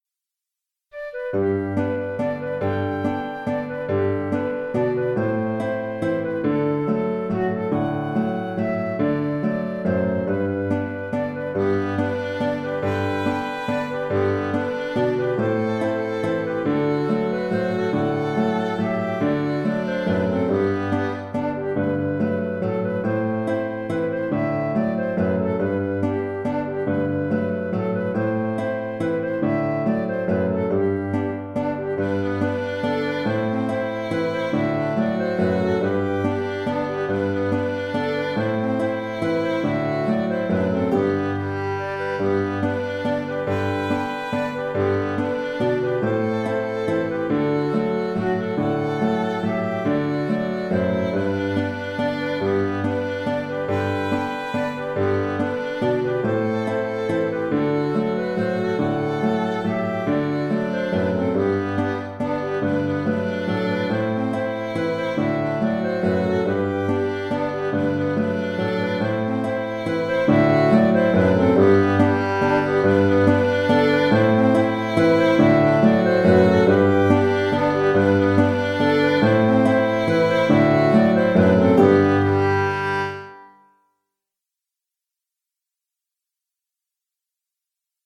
Marguerite (Mazurka) - Musique folk
Cette mazurka, toute simple et très mélodique, est très agréable à jouer. Le contrechant que je propose est aussi très simple.